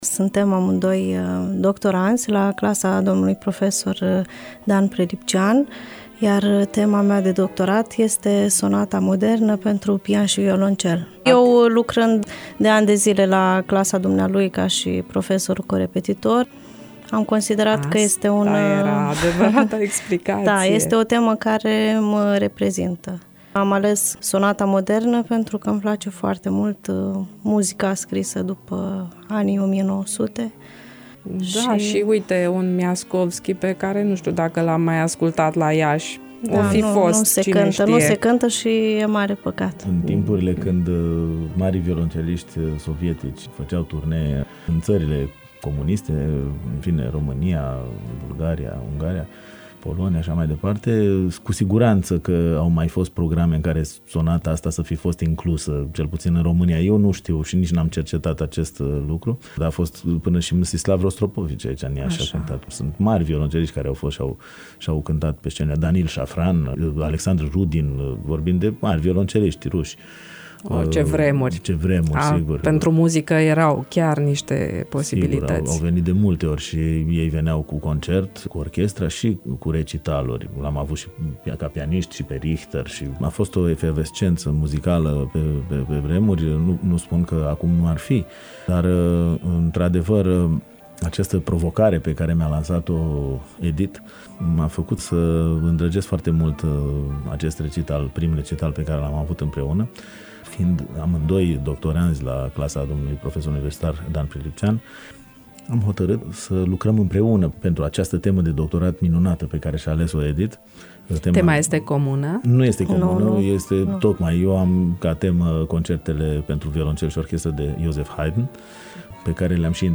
Urmează un scurt fragment din emisiunea Univers Muzical în care au fost invitaţi cei doi artişti ieşeni (26 aprilie 2014):